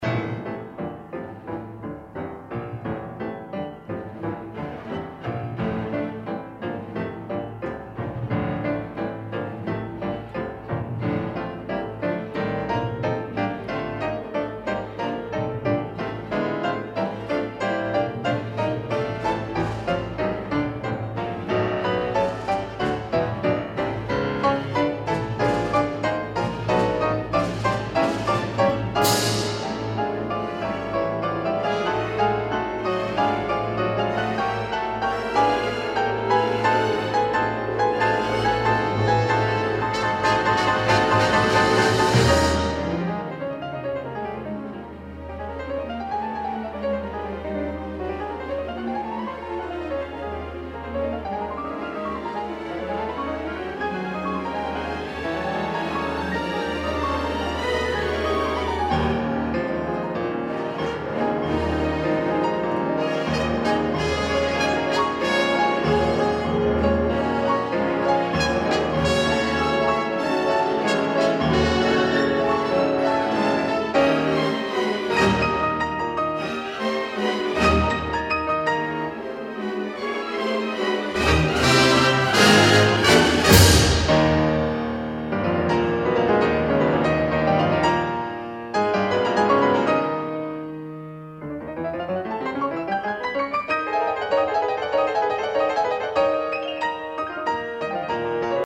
进行曲风格始，乐队出现主题片断，钢琴奏和弦，变为三连音符音群。乐队再出现主题片断，钢琴装饰